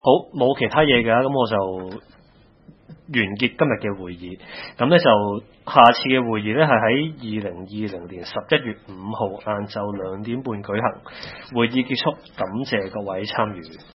南区区议会大会的录音记录
南区区议会会议室